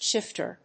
/ˈʃɪftɝ(米国英語), ˈʃɪftɜ:(英国英語)/